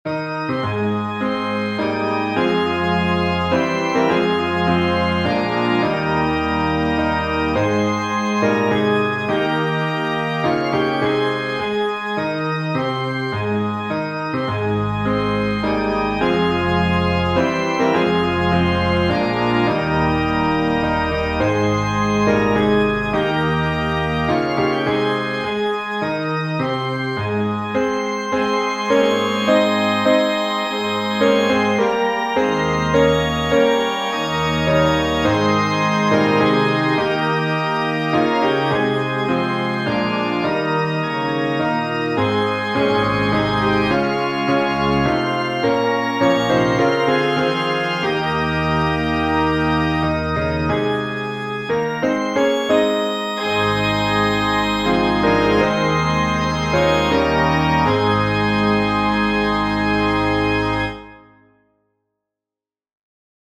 This simplified organ piece may be played as an Organ Solo or Organ / Piano Duet, with or without Choir or Congregation singing.